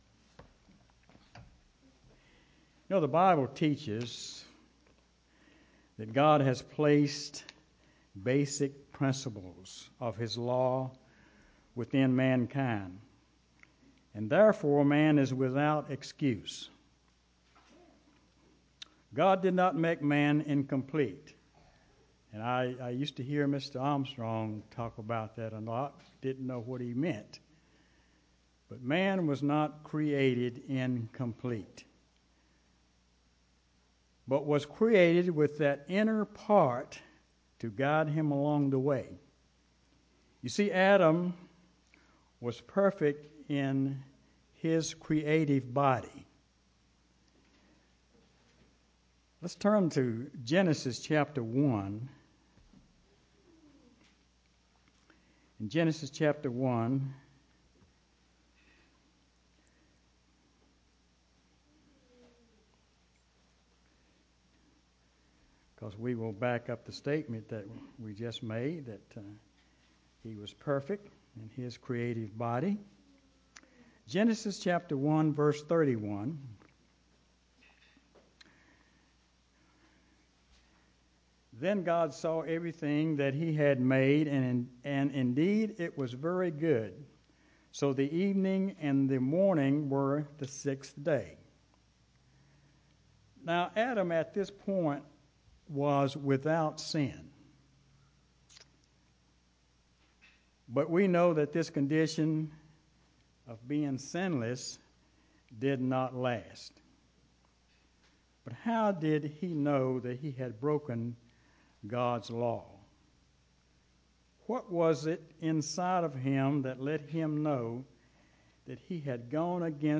UCG Sermon Studying the bible?
Given in Greensboro, NC